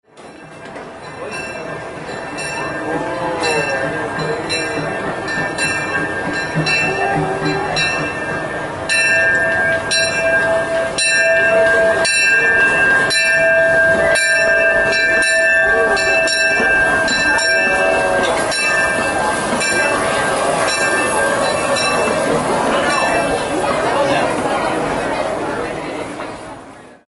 Approach the Main St. Station.
railroad.mp3